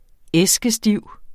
Udtale [ ˈεsgəˈsdiwˀ ]